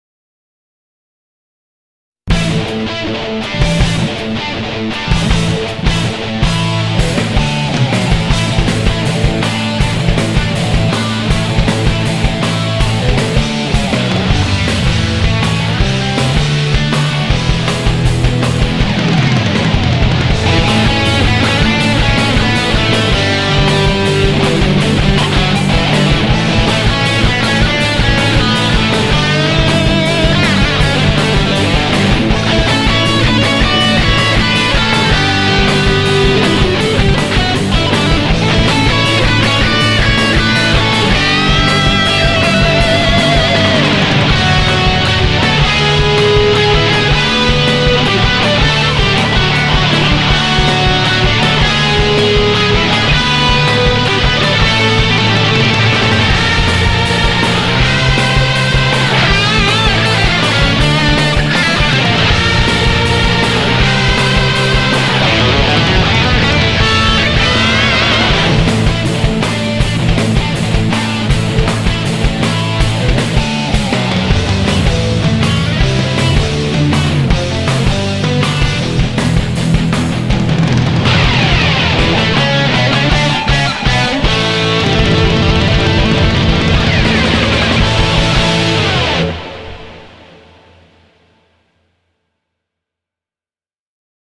기타가 인상적입니다.